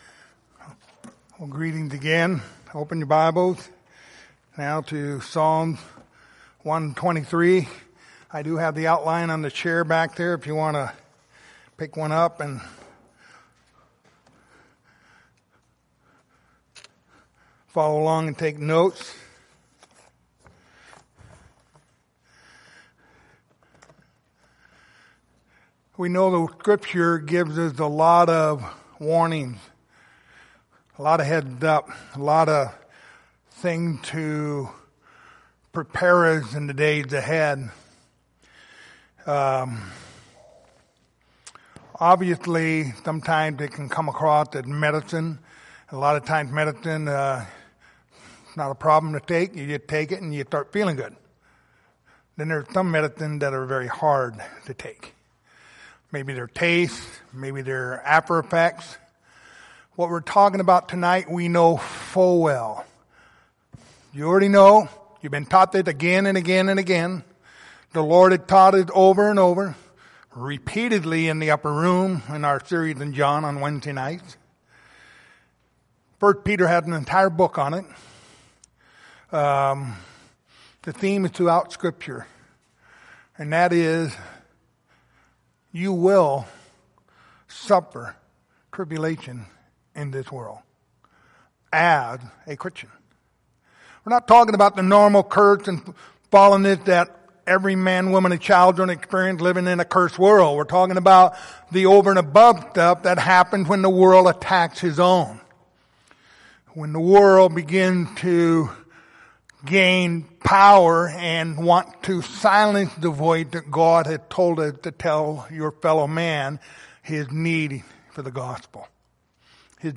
Passage: Psalms 123:1-4 Service Type: Sunday Evening